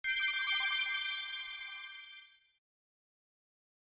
Play Ucn Item Sound - SoundBoardGuy
ucn-item-sound.mp3